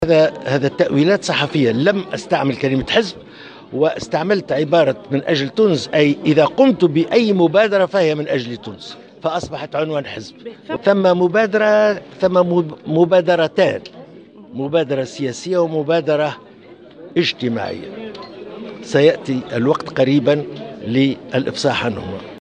أكد الأمين العام لاتحاد المغرب العربي الطيب البكوش في تصريح لمراسلة الجوهرة "اف ام" اليوم الأربعاء على هامش ندوة حول مشروع القطار المغاربي إن الأخبار التي تشاع حول نيته تأسيس حزب جديد هي مجرد تأويلات اعلامية حسب تعبيره.